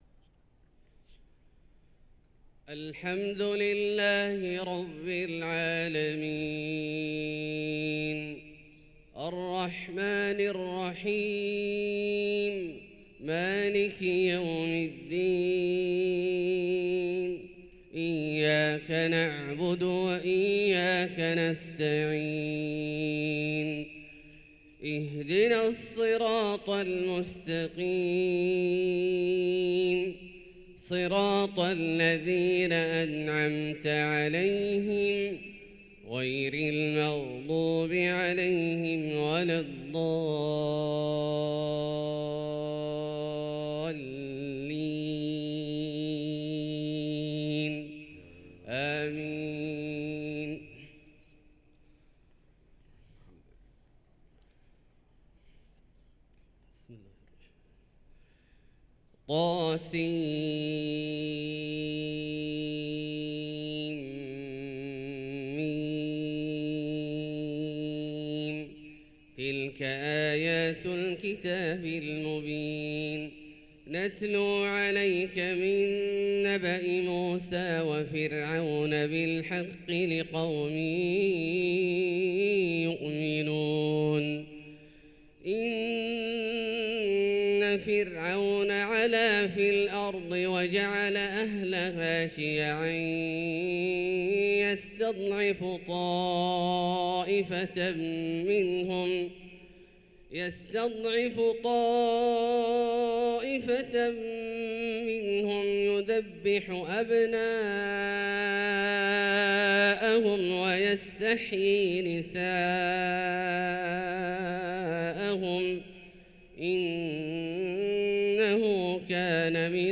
صلاة الفجر للقارئ عبدالله الجهني 25 ربيع الأول 1445 هـ